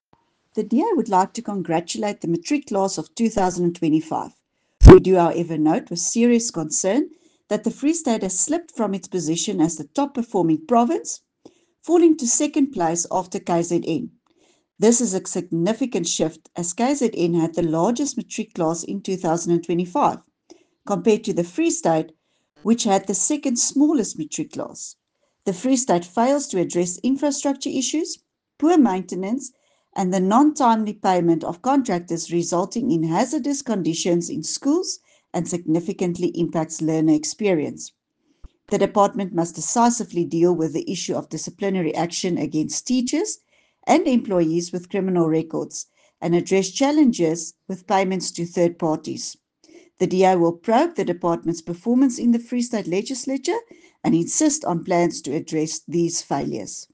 Issued by Dulandi Leech – DA Spokesperson on Education in the Free State Legislature
Afrikaans soundbites by Dulandi Leech MPL and